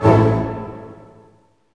Index of /90_sSampleCDs/Roland L-CD702/VOL-1/HIT_Dynamic Orch/HIT_Orch Hit Maj
HIT ORCHM0FR.wav